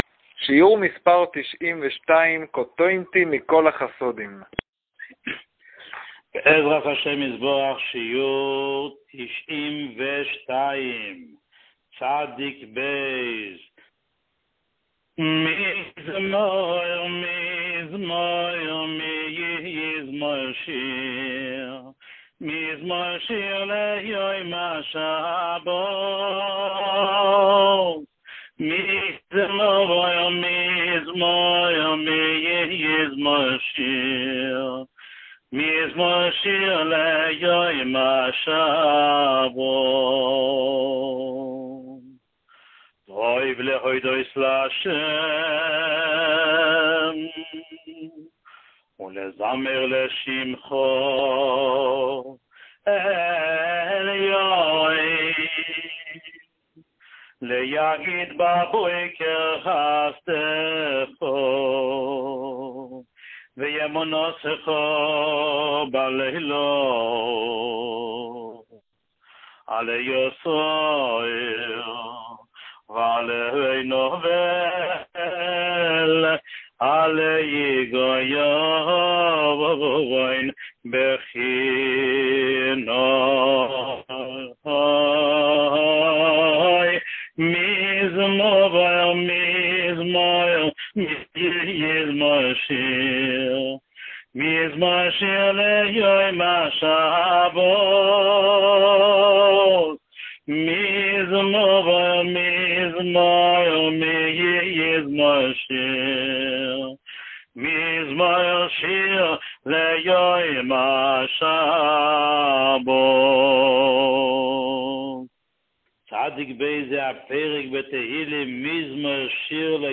שיעור 92